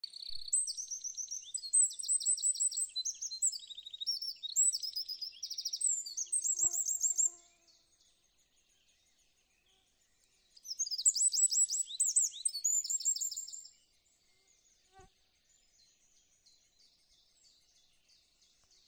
Wren, Troglodytes troglodytes
Administratīvā teritorijaViļakas novads
StatusInhabited nest or cavity
NotesUztraukts padziedāja, redzēts ielidojam un izlidojam.